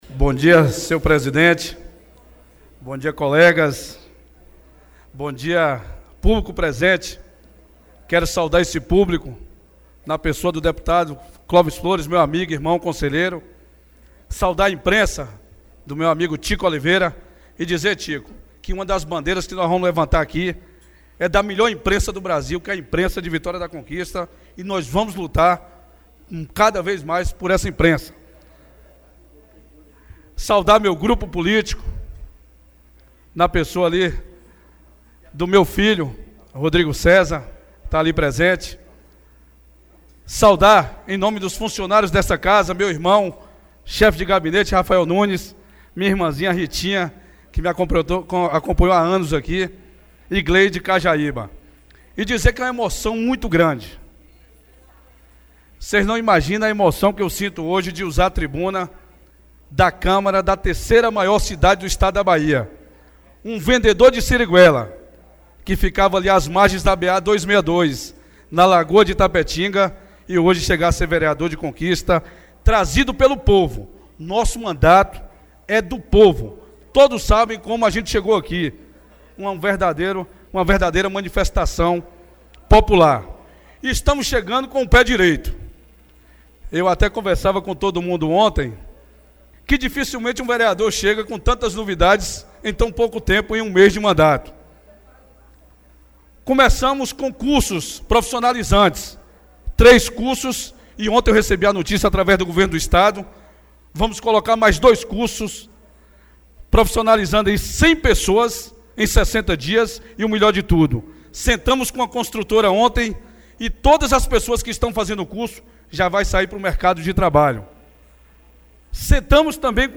Em seu primeiro discurso no plenário da Câmara de Vereadores de Vitória da Conquista, o vereador Ricardo Gordo (PSB) agradeceu o apoio de todo o seu grupo político, amigos e familiares pela conquista do mandato parlamentar e declarou: “vocês não imaginam a alegria de estar aqui, um vendedor de ciriguela, hoje vereador da terceira maior cidade da Bahia”, comemorou.